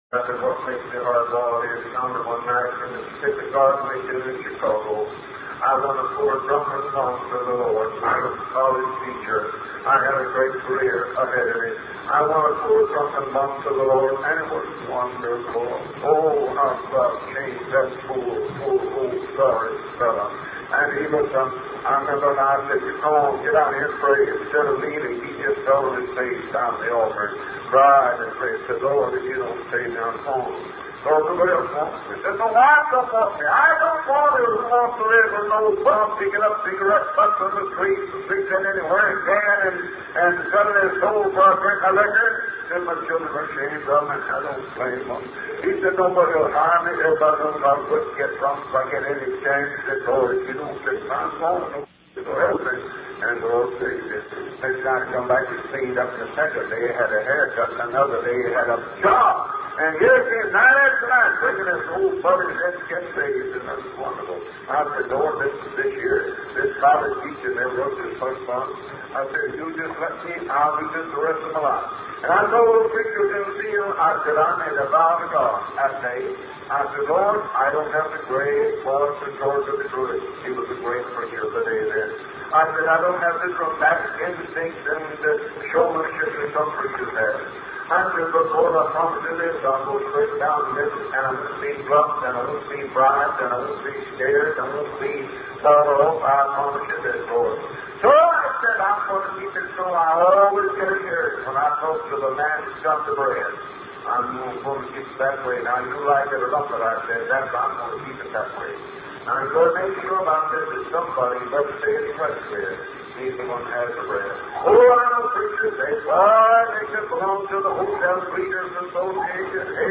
The preacher emphasizes the power of prayer and encourages the congregation to rely on God for their needs.